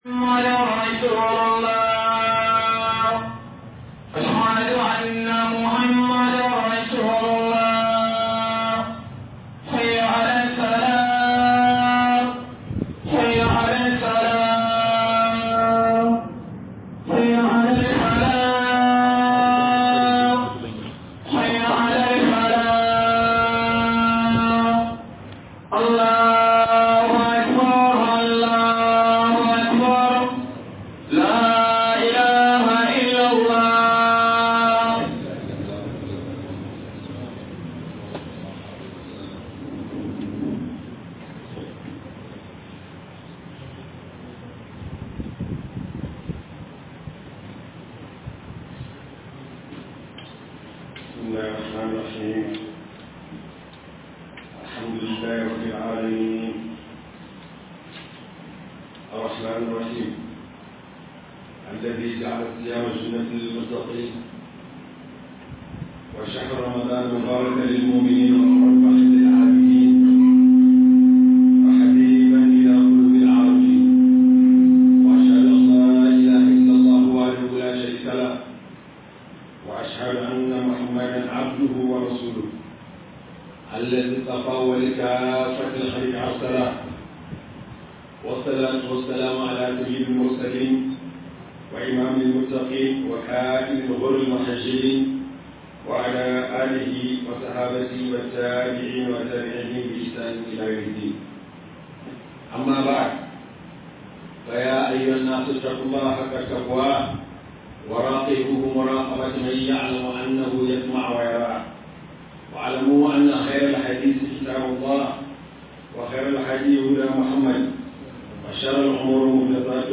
011 Khudbah Akan Ramadan.mp3